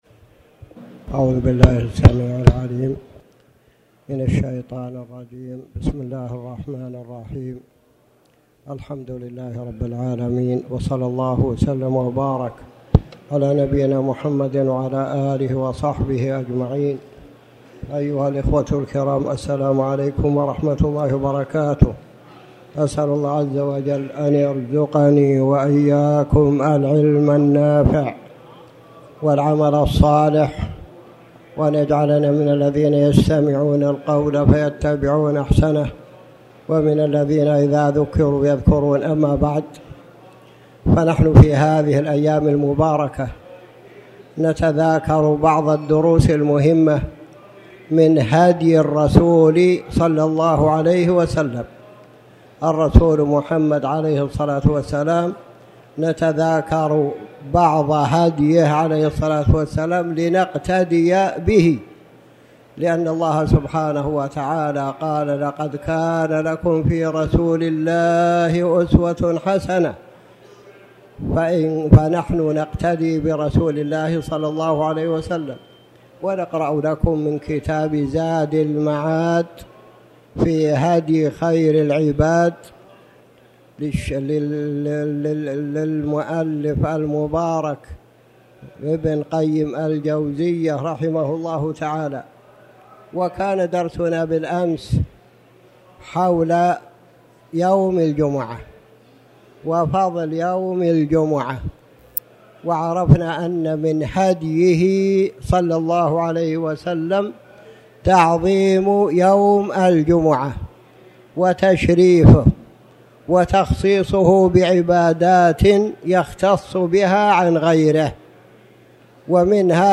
تاريخ النشر ١٨ ذو الحجة ١٤٣٩ هـ المكان: المسجد الحرام الشيخ